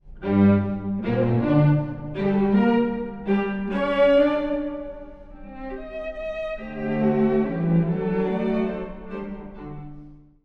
第2楽章…爽やかなメヌエットと彷徨うトリオ
モーツァルトにとって宿命的な調性ともいいうる「ト短調」で書かれています。
暗いですが、気品の高さも感じます。